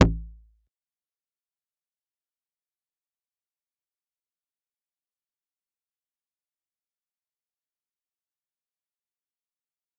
G_Kalimba-D1-pp.wav